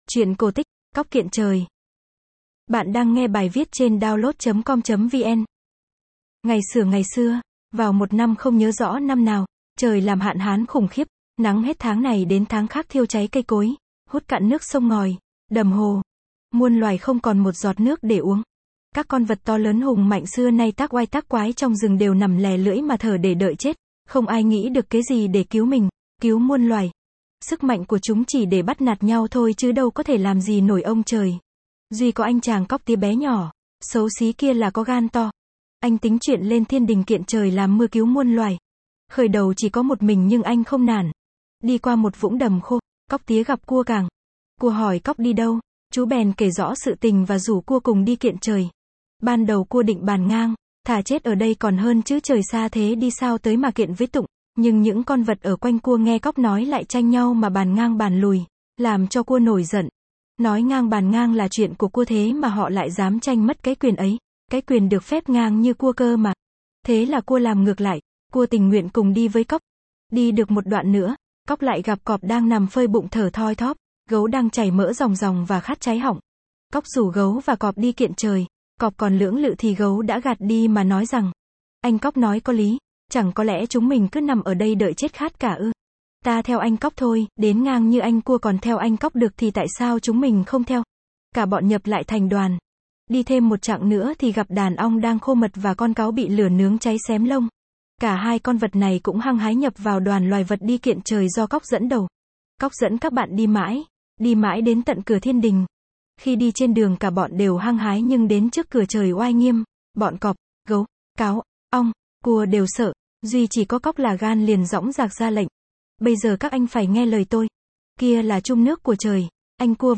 Sách nói | cóc kiện trời